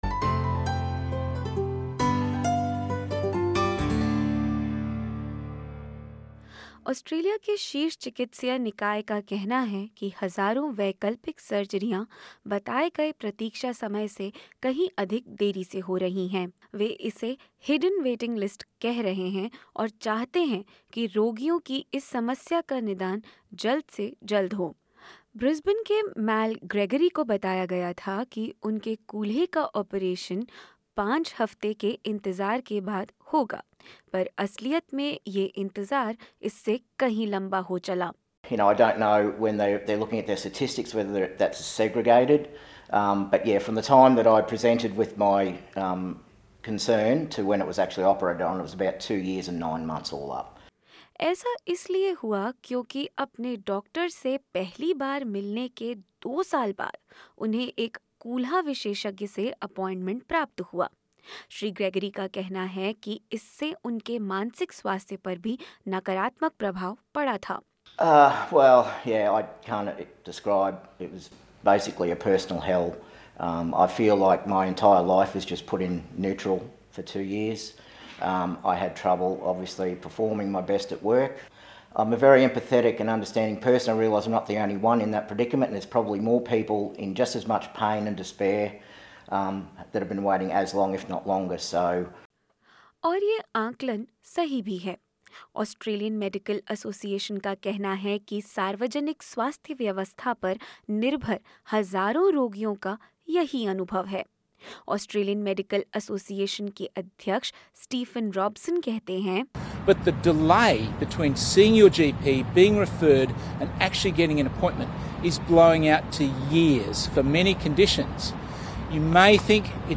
ऑस्ट्रेलिया में वैकल्पिक सर्जरी के मरीज़ों को अपनी बारी आने का लंबा इंतज़ार करना पड़ता है। हाल ही में ऑस्ट्रेलियन मेडिकल असोसिअशन ने 'हिडन वेटिंग लिस्ट' की बात की है, जिसके अनुसार एक रोगी को उपचार के लिए बताये गए समय से कहीं ज़्यादा प्रतीक्षा करनी पड़ती है। क्या है यह हिडन वेटिंग लिस्ट, और क्यों करना पड़ रहा है रोगियों को इतना इंतज़ार, जानेंगे इस रिपोर्ट में।